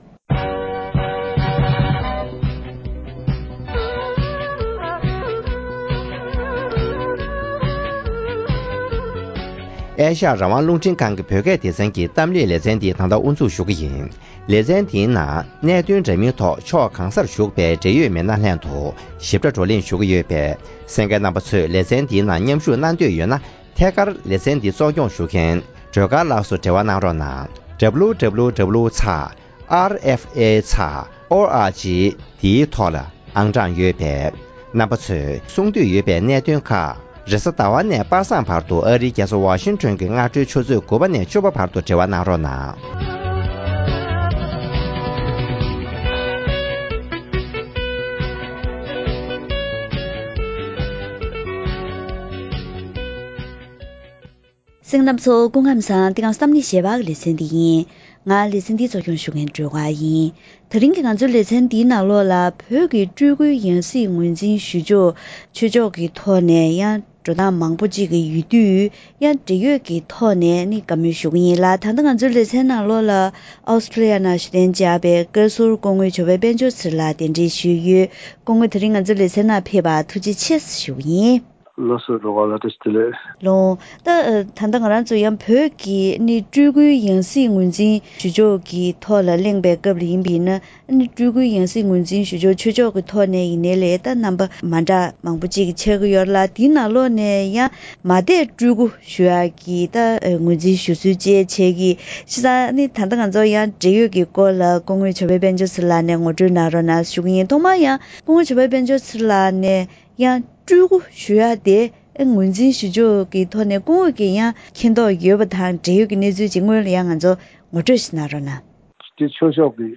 གཏམ་གླེང་ཞལ་པར་ལེ་ཚན་འདིའི་ནང་སྤྲུལ་སྐུའི་ཡང་སྲིད་ངོས་འཛིན་ཞུ་ཕྱོགས་ཐད་ཆོས་ཕྱོགས་ཀྱི་གནང་སྲོལ་ཁག་ཡོད་པ་དང་། ལྷ་མེད་སྨྲ་བའི་དམར་ཤོག་གཞུང་གིས་བོད་ཀྱི་སྤྲུལ་སྐུའི་ཡང་སྲིད་ངོས་འཛིན་ཐོག་ཐེ་ཇུས་རིམ་པ་བཞིན་བྱེད་ཀྱི་ཡོད་པས། ལོ་རྒྱུས་དང་ད་ལྟའི་གནས་སྟངས་སོགས་ཀྱི་ཐོག་འབྲེལ་ཡོད་དང་བཀའ་མོལ་ཞུས་པ་ཞིག་གསན་རོགས་གནང་།